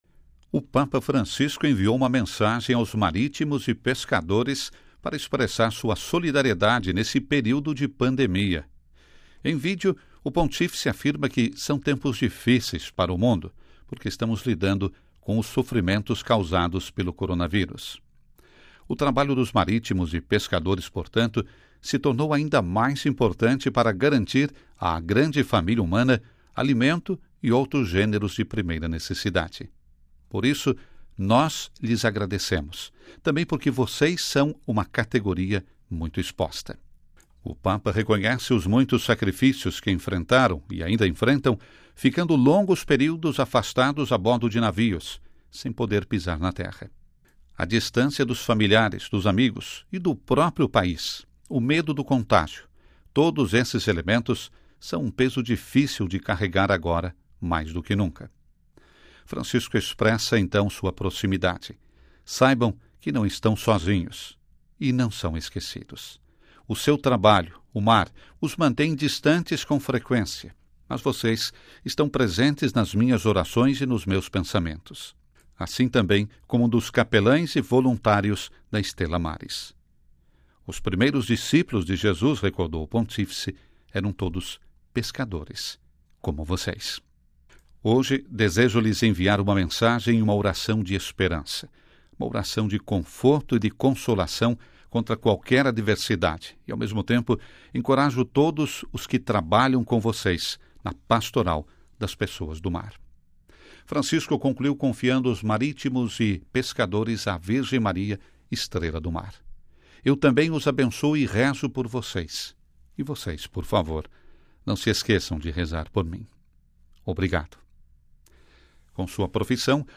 O Papa Francisco enviou uma mensagem aos marítimos e pescadores, para expressar sua solidariedade neste período de pandemia.